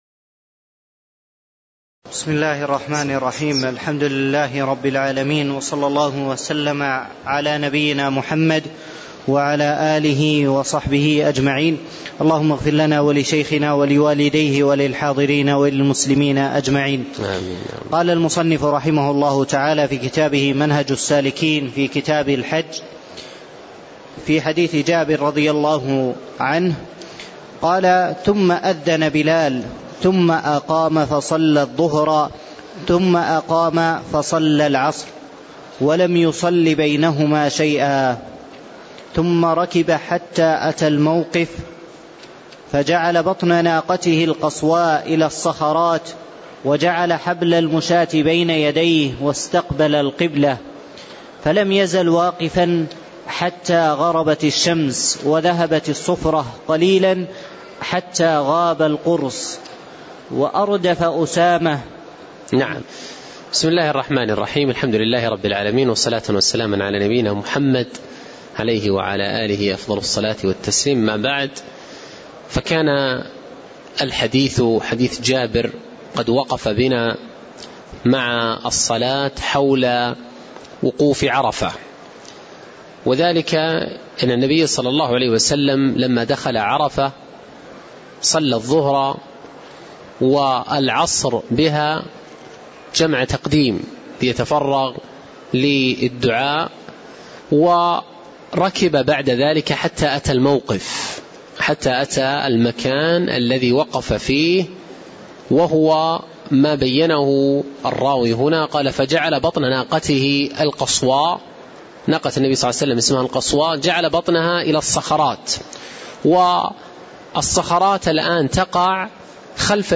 تاريخ النشر ١٨ شوال ١٤٣٧ هـ المكان: المسجد النبوي الشيخ